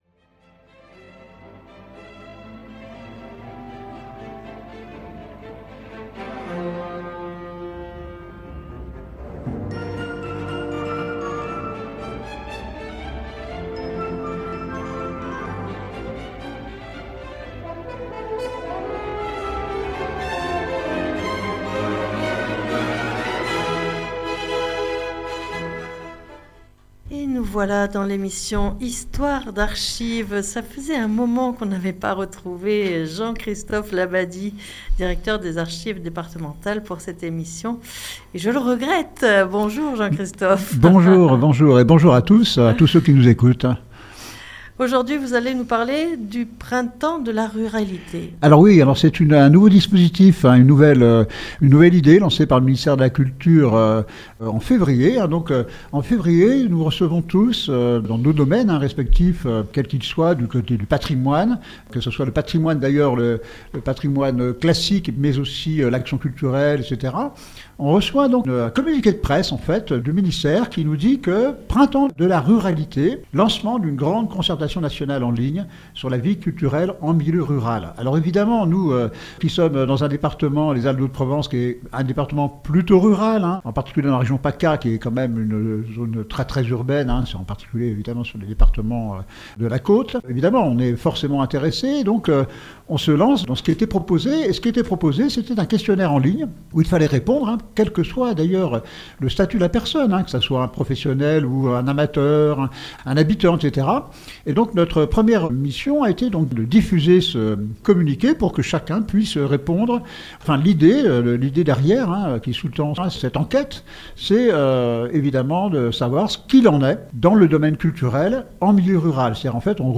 Cette émission sur fréquence mistral à Digne, a lieu tous les 3èmes jeudi du mois en direct de 9h10 à 10h